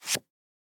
card_drag.ogg